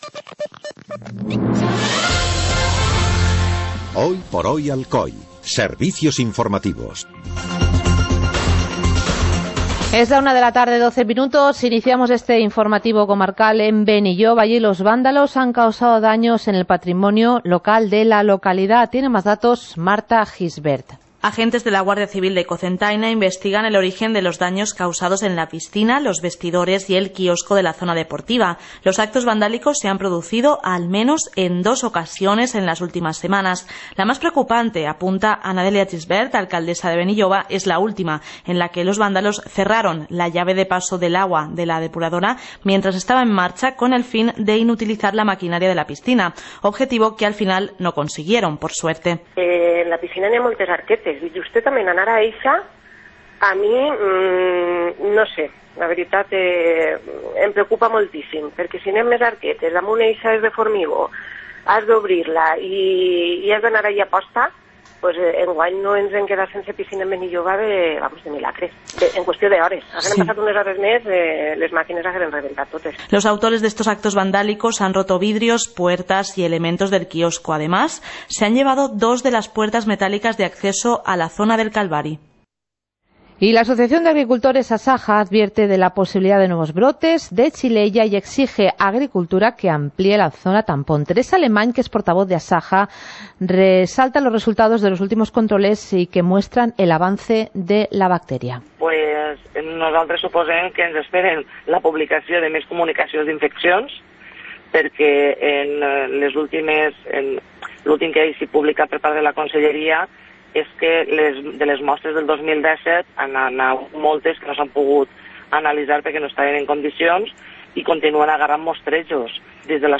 Informativo comarcal - jueves, 26 de julio de 2018